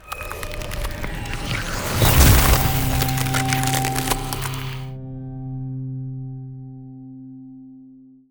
heal.wav